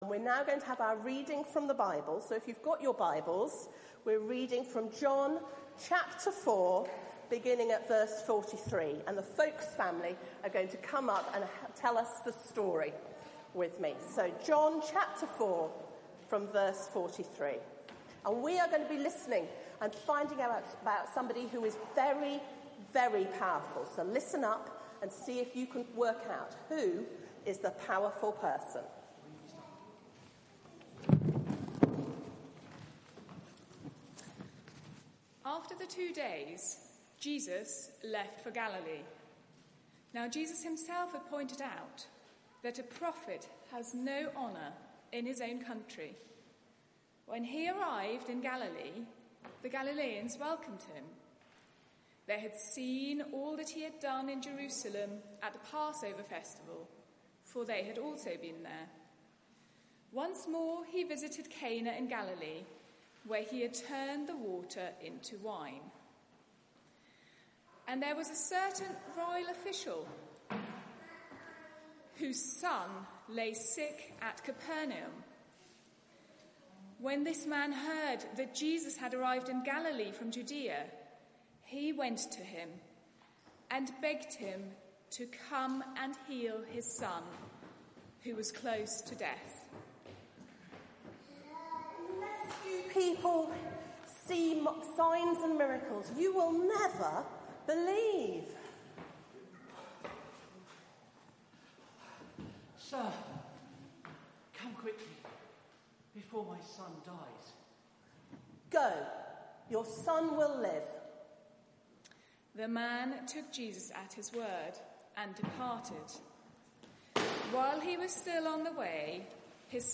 Media for 9:15am Service on Sun 09th Aug 2020 10:00
Series: Meetings with Jesus (in John's Gospel) Theme: Jesus' word is all powerful Talk